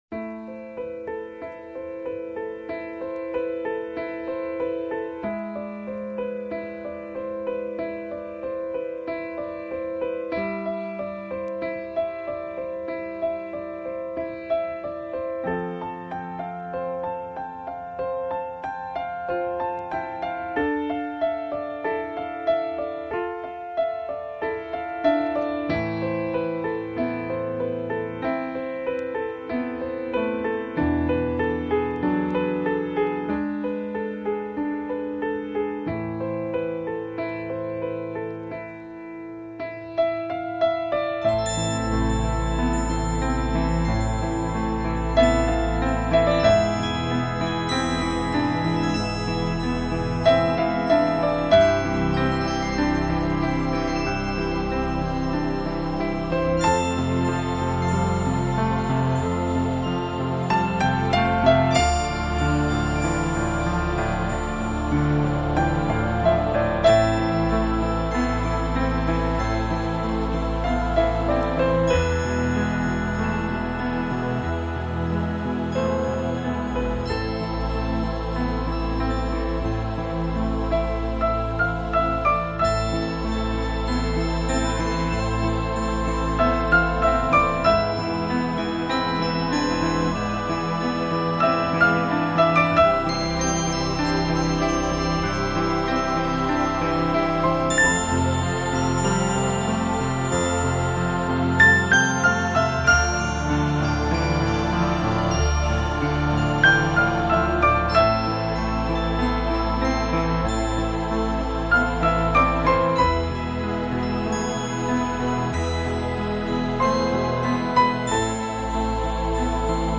Немного фортепиано.